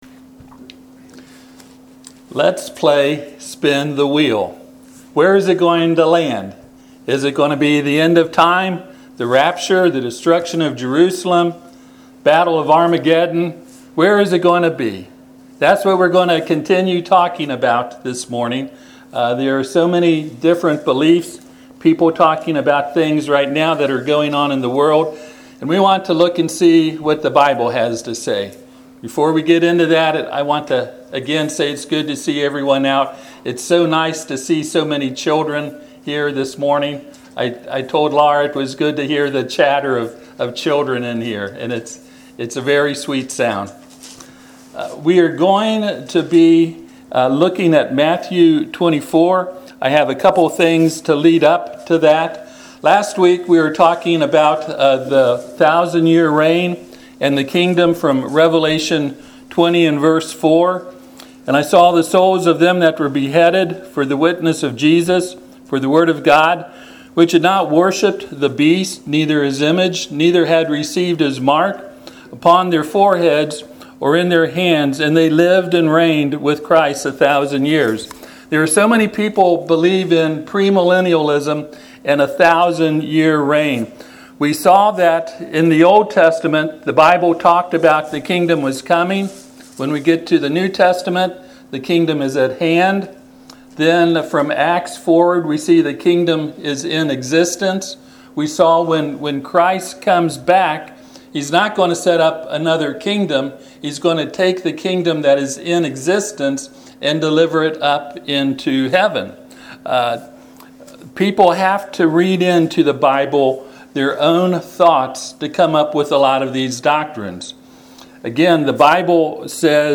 Service Type: Sunday AM Topics: destruction of Jerusalem , end of time , premillennialism , rapture « Praise Worship And Thanksgiving.